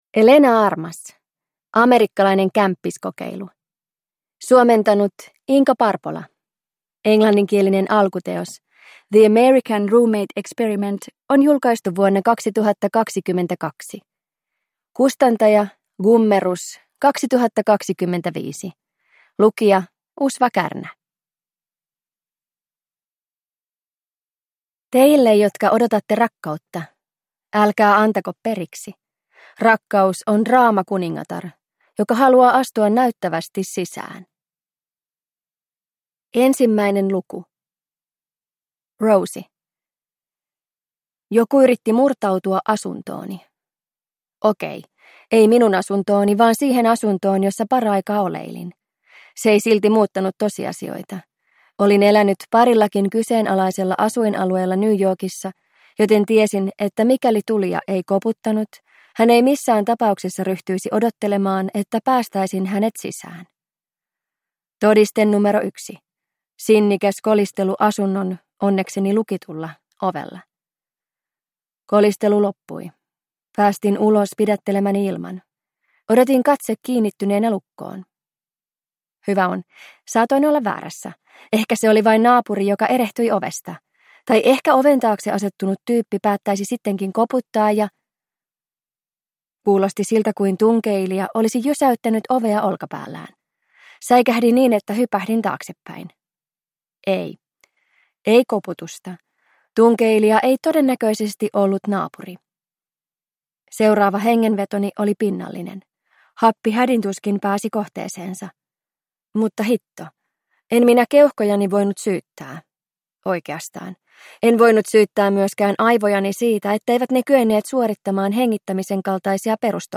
Amerikkalainen kämppiskokeilu – Ljudbok